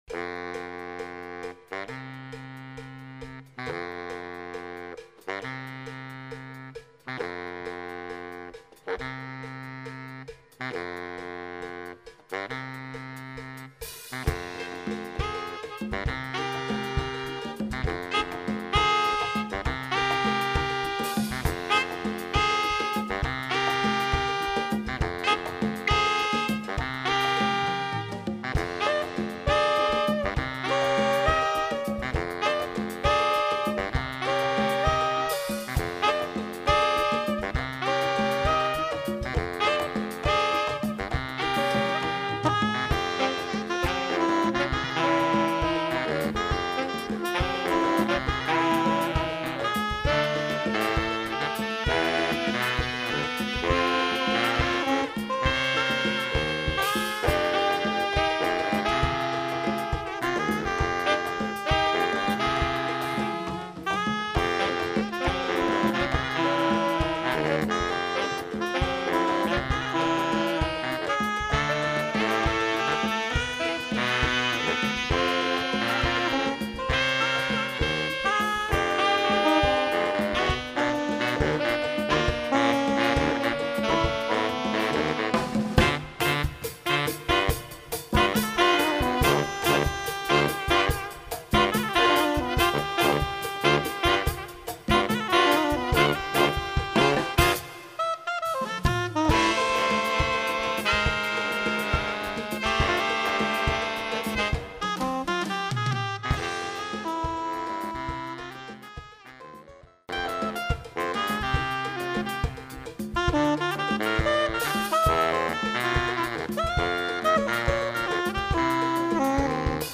Category: sax quartet
Style: bembe (6/8)